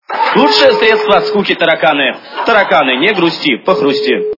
» Звуки » Смешные » Лучшее средство от скуки - тараканы! - Тарканы-не грусти, похрусти!
При прослушивании Лучшее средство от скуки - тараканы! - Тарканы-не грусти, похрусти! качество понижено и присутствуют гудки.